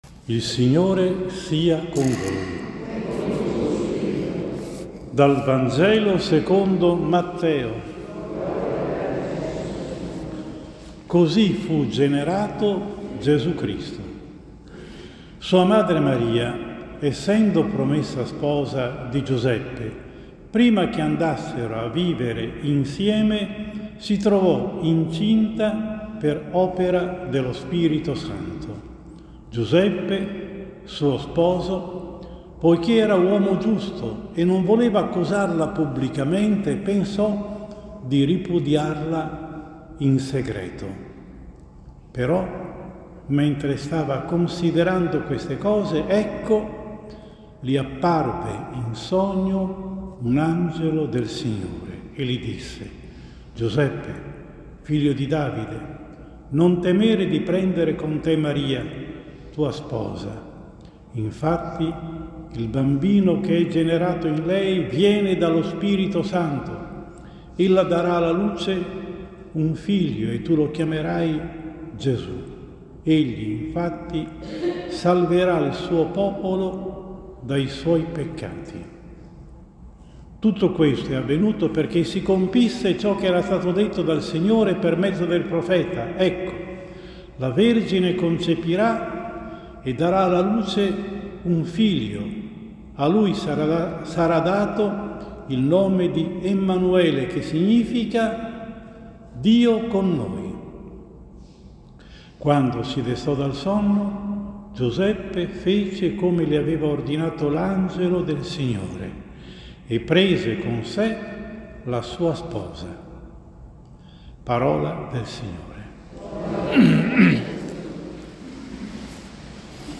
18 dicembre 2022 – Quarta Domenica di Avvento: omelia